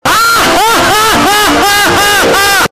Tidus laugh (Ear rape)